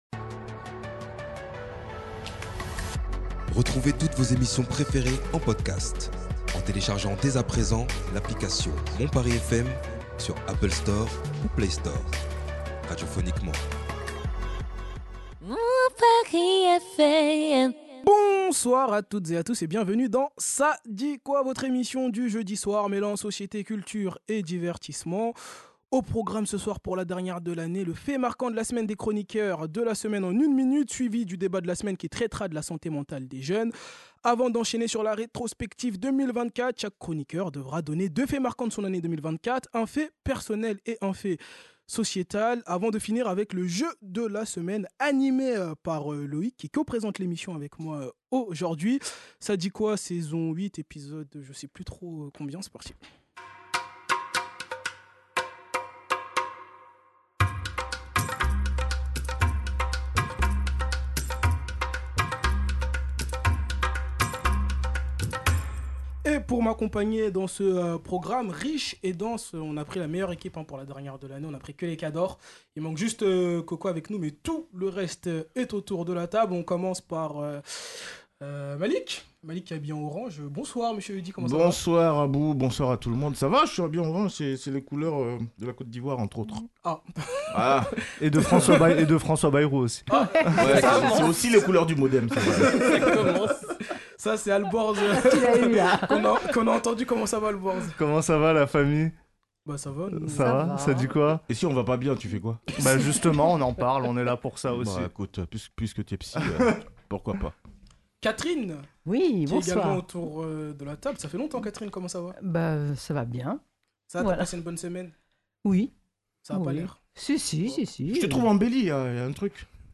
Dans ce dernier épisode de l'année 2024, les chroniqueurs commenceront par résumer en une minute le fait marquant de la semaine.